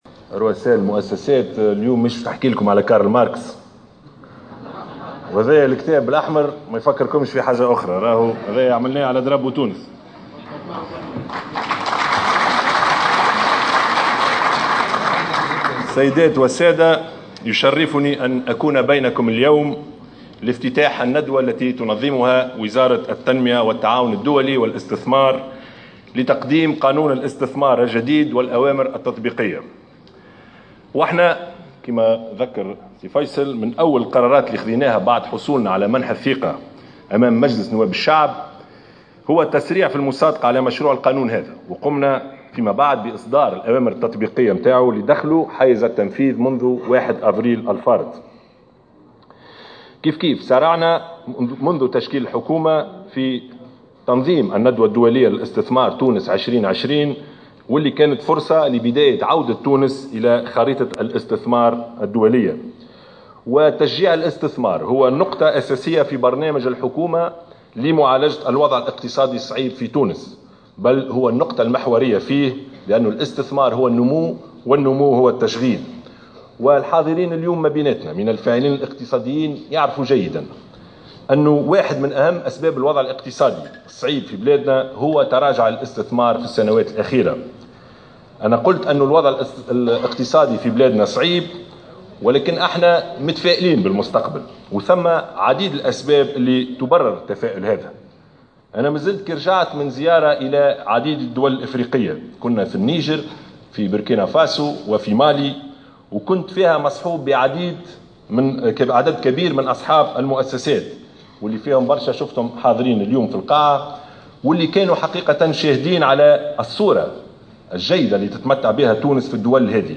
وأكد رئيس الحكومة، في كلمته خلال ندوة، انعقدت اليوم السبت بأحد نزل العاصمة، حول الإطار القانوني الجديد للإستثمار، أن رحلته الإفريقية الأخيرة أثبتت الصورة الإيجابية التي تتمتع بها تونس في هذه الدول، مشيرا إلى أن هذه السمعة الجيدة تعكس ما تتمتع به بلادنا من إمكانيات تسمح لها بتحقيق قفزة اقتصادية في أسرع وقت.